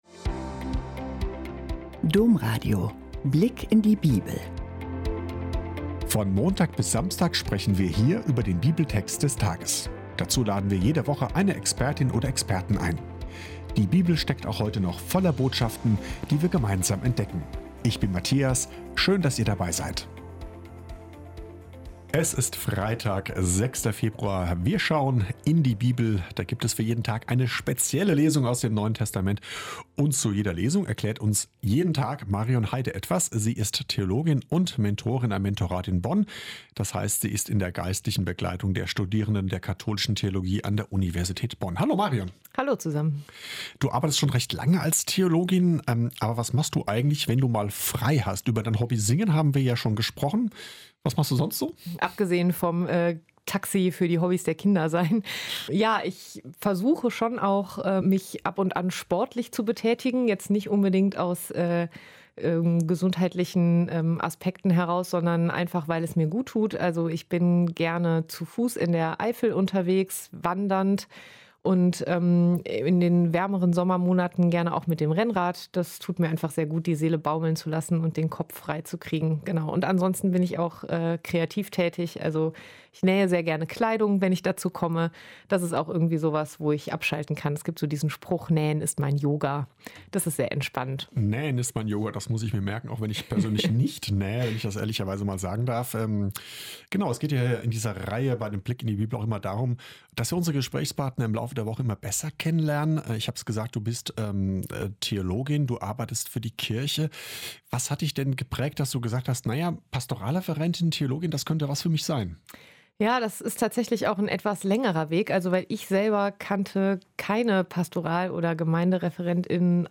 Gespräch
spricht DOMRADIO-Redakteur
mit der Theologin